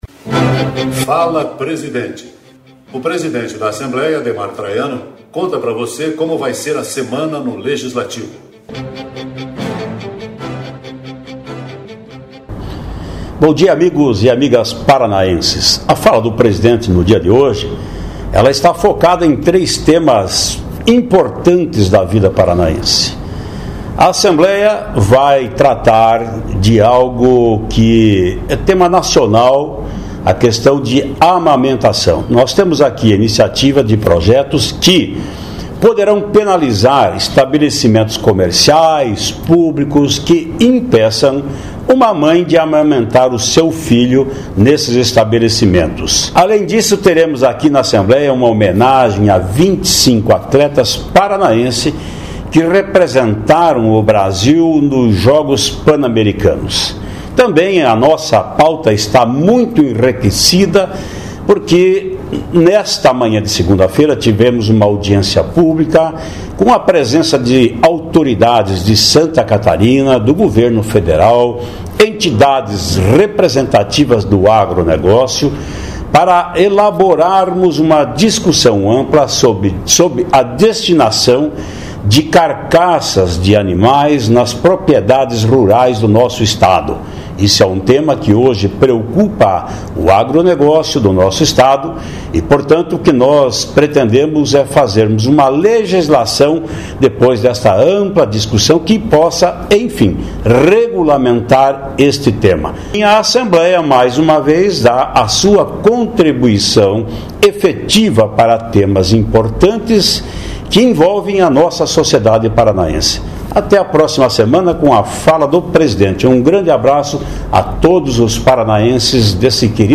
(Áudio  do presidente Ademar Traiano))